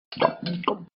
Звук голосового эффекта персонажа Бена - Кушает 3